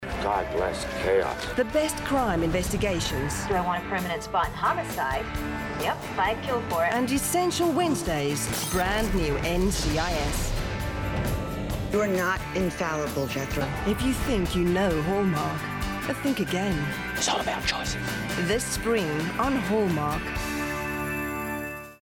Approachable, Authoritative, Confident, Conversational, Corporate, Gravitas, Natural, Reassuring, Warm
South African
My voice has been described as warm, earthy, sensual, clear and authoritative.
Afrikaans_Female_Medical_IVR.mp3